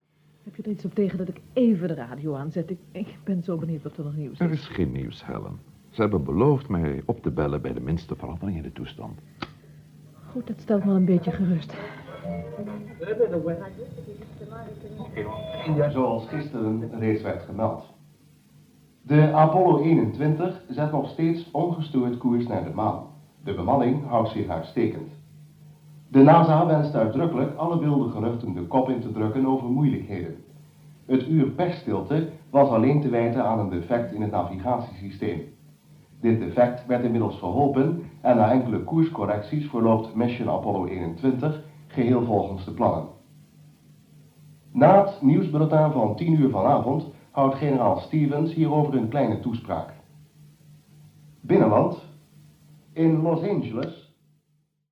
– Apollo XXI (Het Maanmysterie) – radio-omroeper